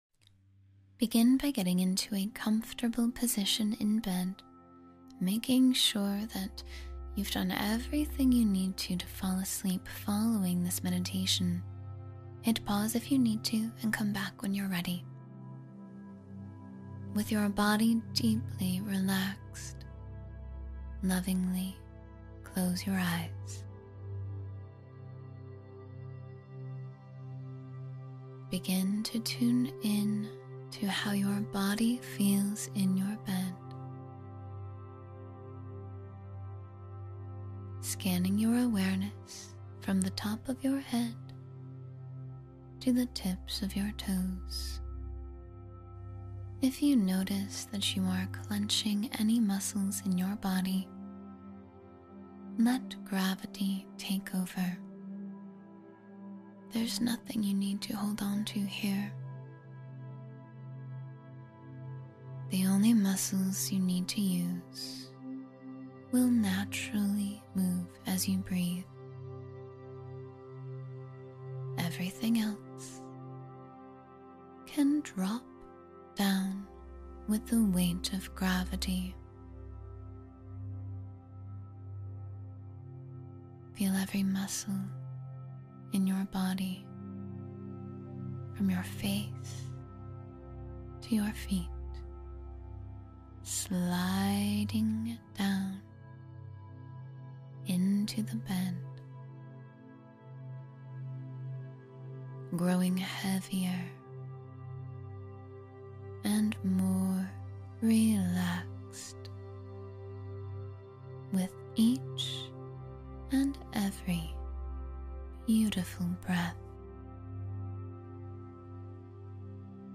Sleep Soundly in 10 Minutes — Guided Meditation for Peaceful Rest